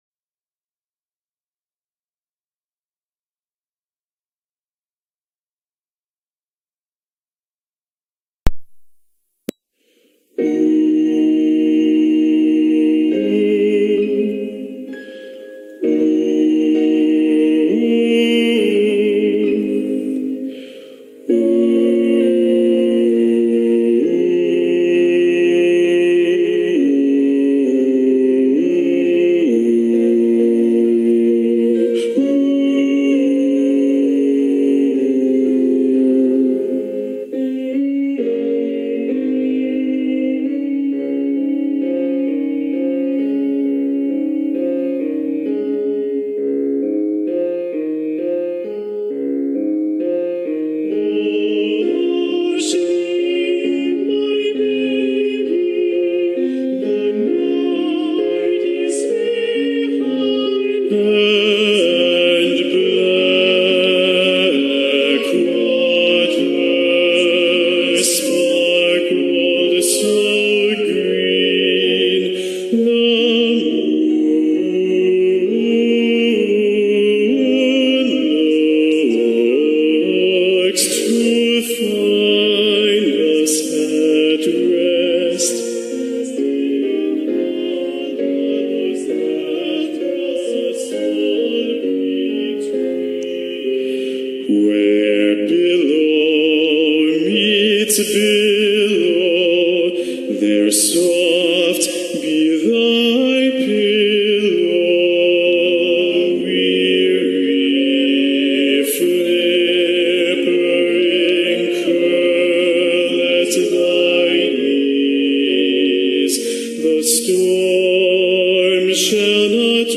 - Œuvre pour chœur à 5 voix mixtes (SATBB) + piano
Basse Chante